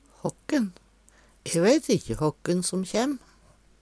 håkken - Numedalsmål (en-US)